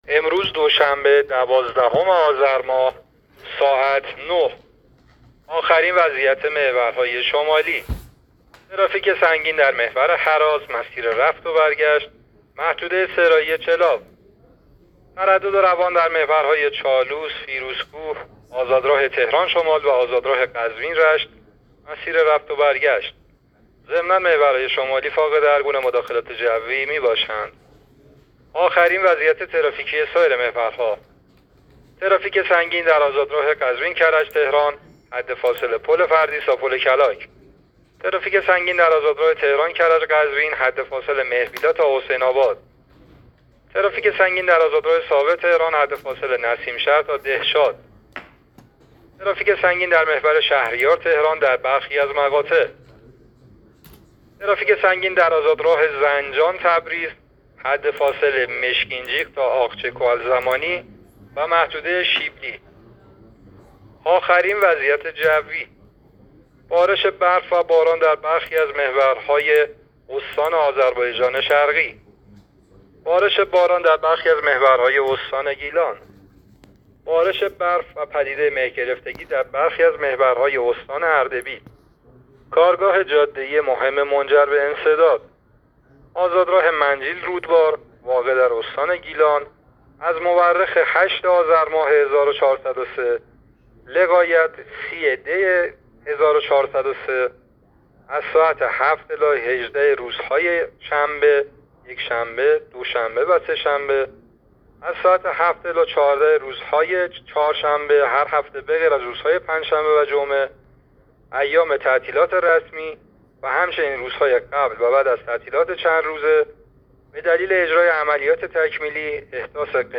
گزارش رادیو اینترنتی از آخرین وضعیت ترافیکی جاده‌ها تا ساعت ۹ دوازدهم آذر؛